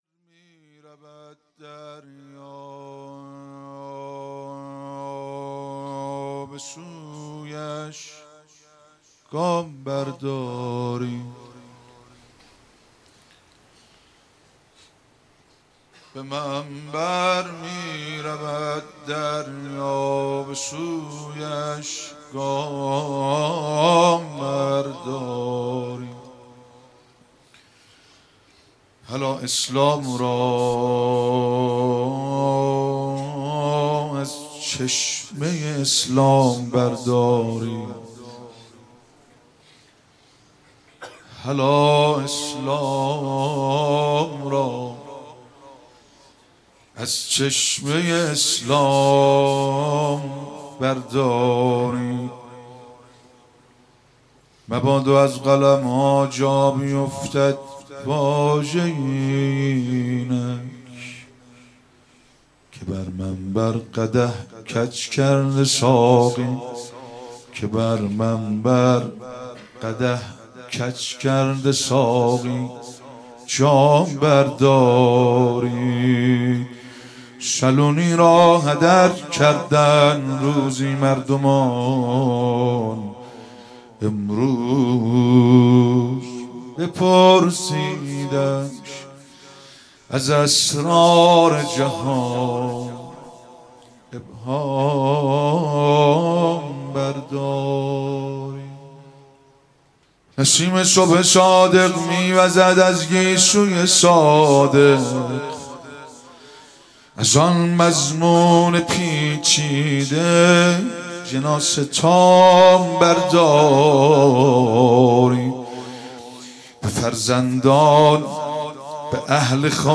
28 تیر 96 - هیئت فاطمیون - روضه امام صادق (ع)